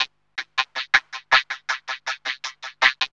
07 Scritchy 170.wav